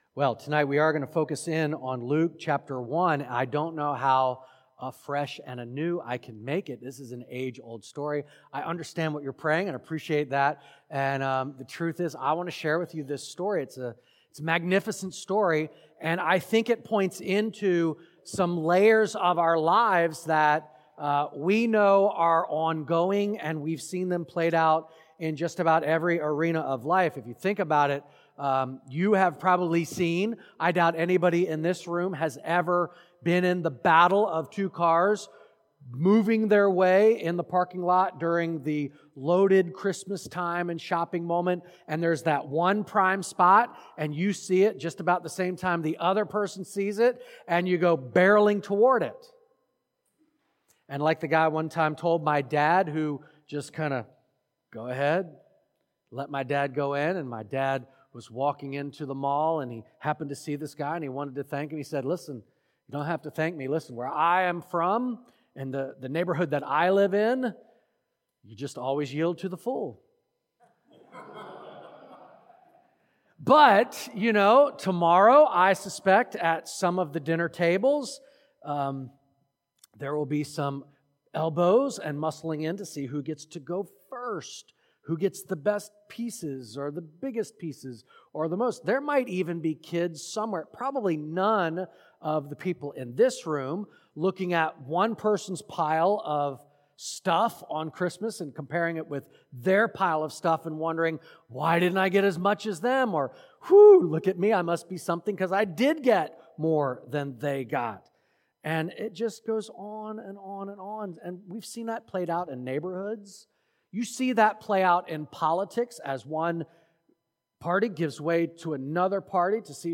Simply Christmas - Christmas Eve Service